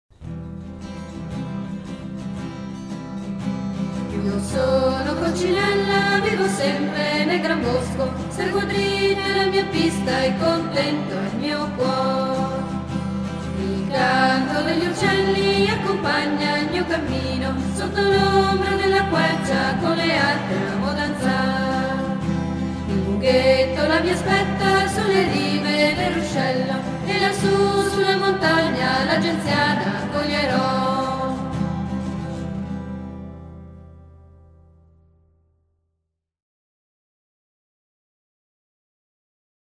Canto del cerchio SOL RE7 SOL Io sono coccinella vivo sempre nel gran bosco, RE7 SOL seguo dritta la mia pista e contento � il mio cuor.